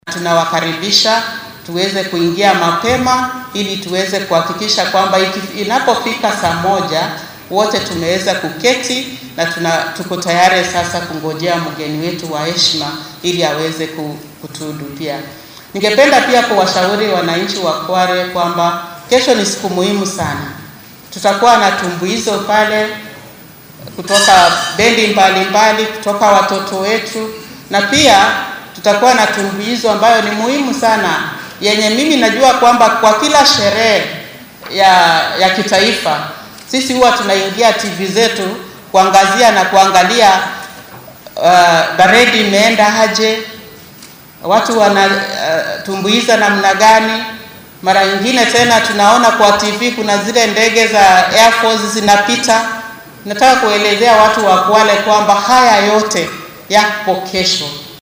Isku duwaha ammaanka ee gobolka Xeebta Rhodah Onyancha oo arrintan ka hadlaysa ayaa tiri.